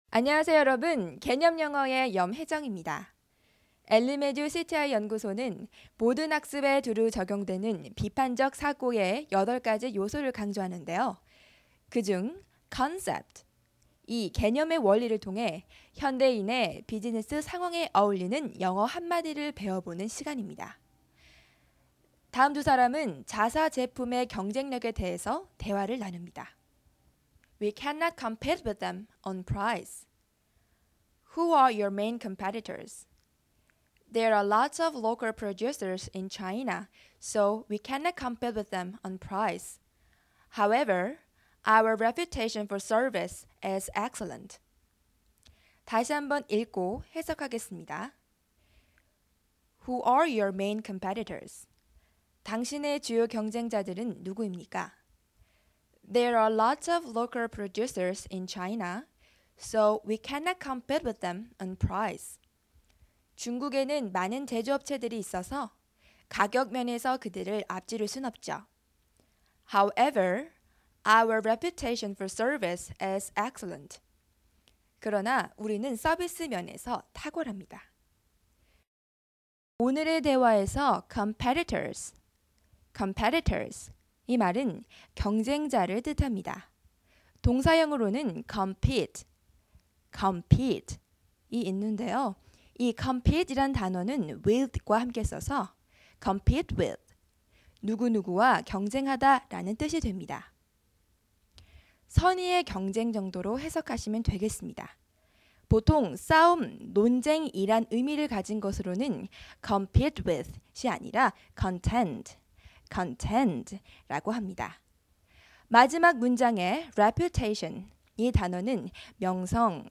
자사 제품의 경쟁력에 대해서 두 사람이 대화를 나눕니다.